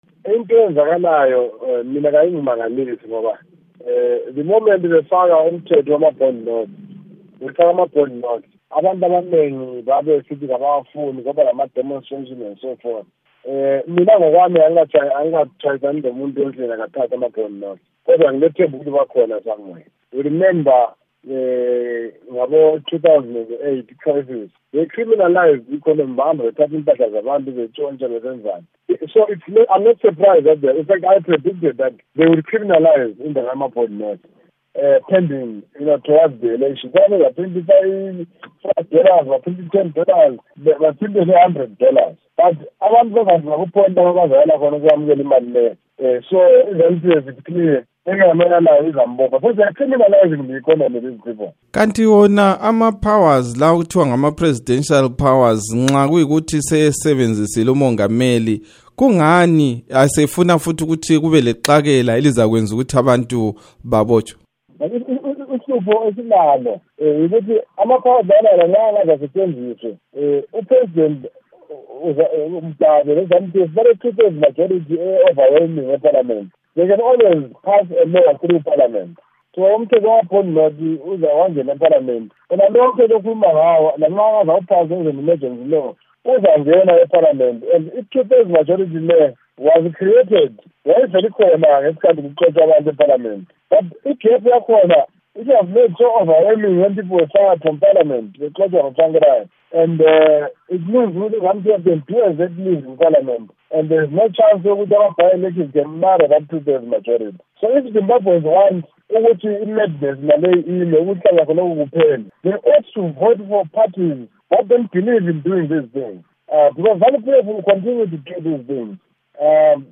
Ingxoxo loMnu. Kucaca Phulu